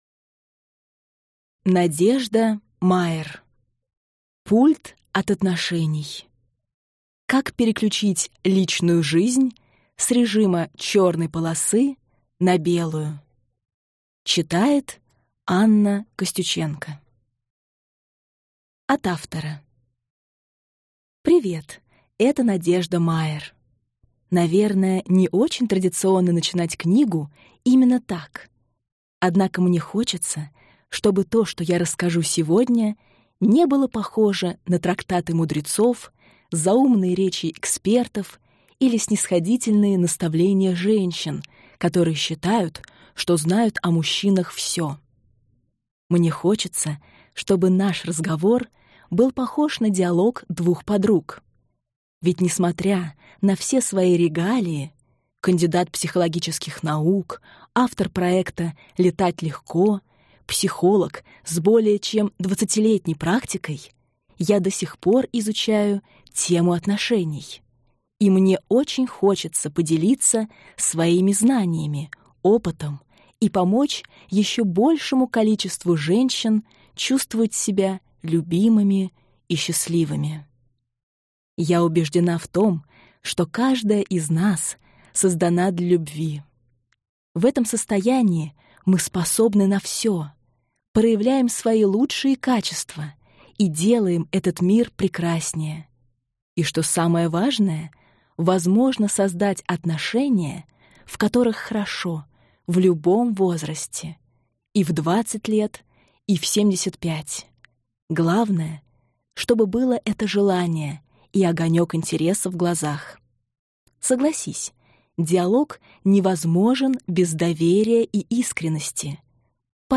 Аудиокнига Пульт от отношений. Как переключить личную жизнь с режима черной полосы на белую | Библиотека аудиокниг